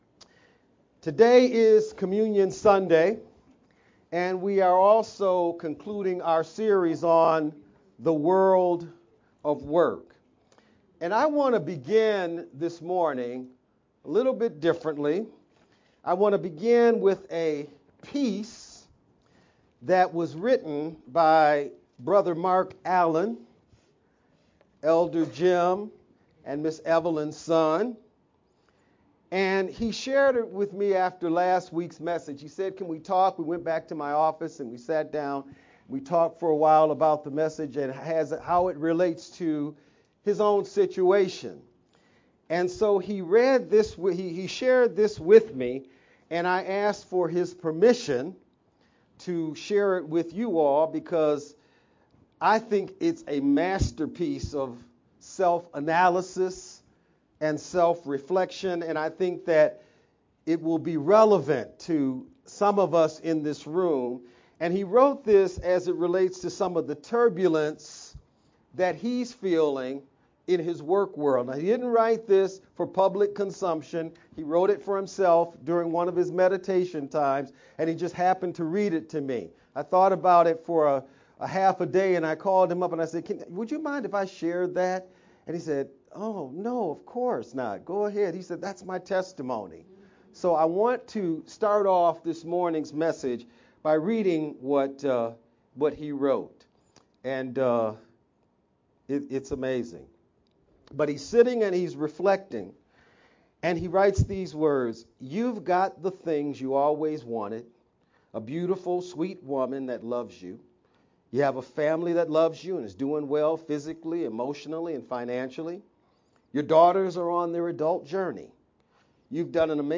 VBCC-Sermon-edited-sermon-only-May-27th_Converted-CD.mp3